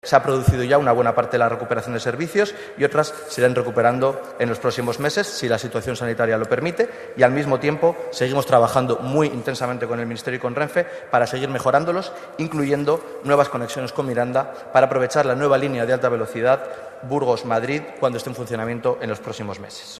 Lo ha asegurado el conejero de Sostenibilidad y Transición Ecológica, Alex Dorado Nájera, en el Parlamento de La Rioja en respuesta a una pregunta de la diputada de IU Henar Moreno que se interesó por cuándo se iban a recuperar todos los servicios de transportes que se suprimieron con la pandemia.